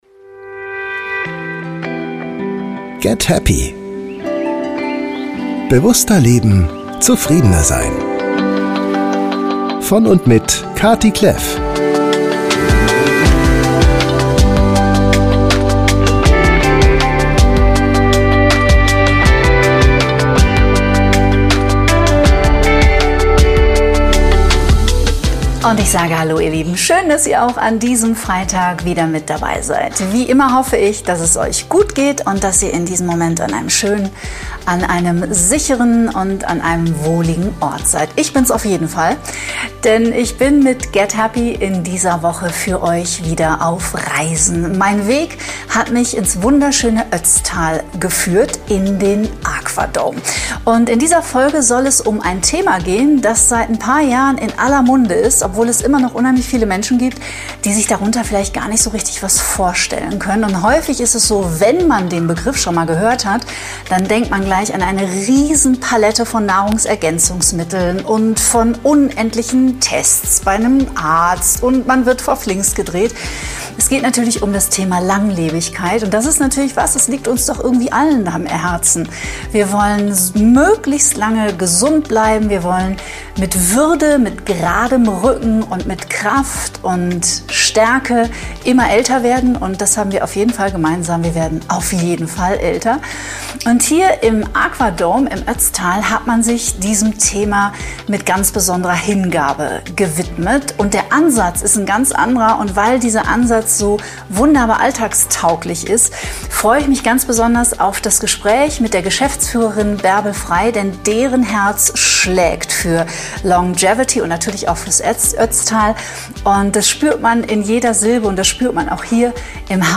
Ich bin einer Presseeinladung in den Aqua Dome in Tirol gefolgt